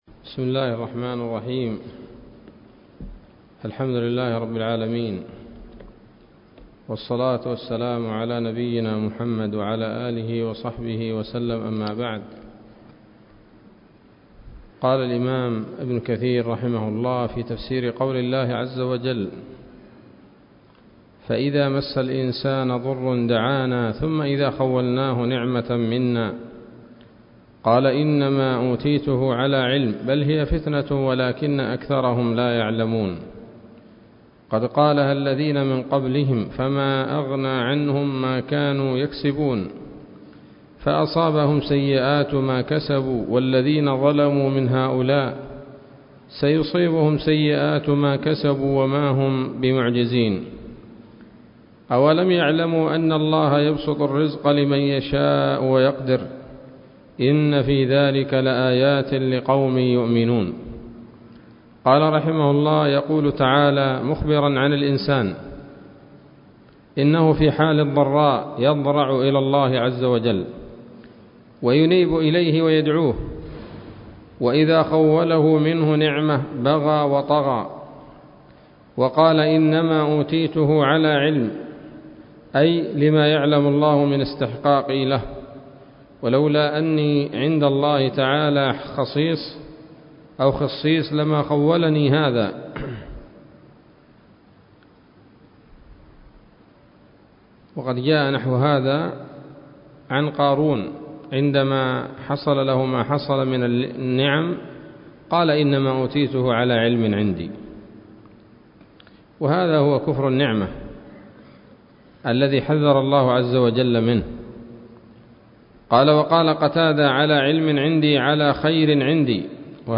039 سورة الزمر الدروس العلمية تفسير ابن كثير دروس التفسير